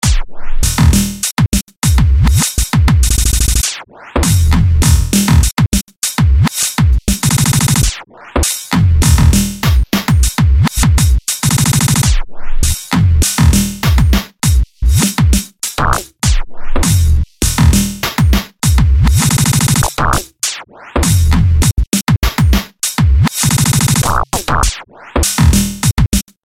Basical glitchery.mp3